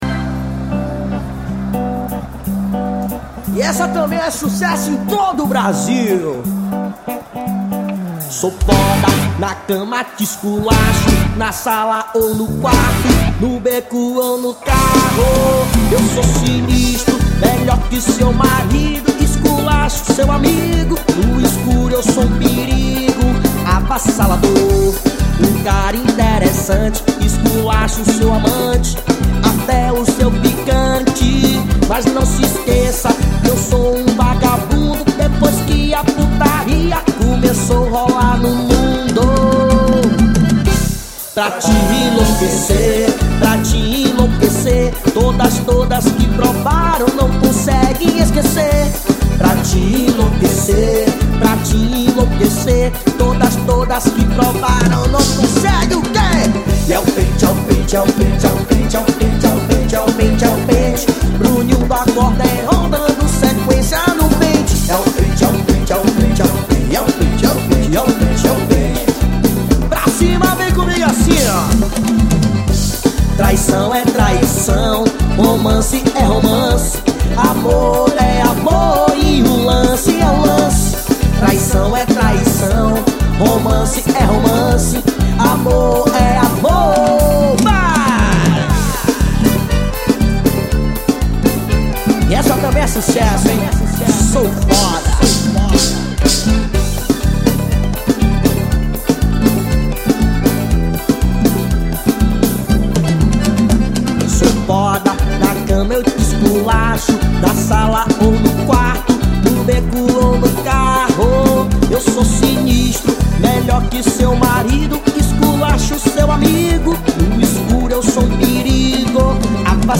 Sertanejo.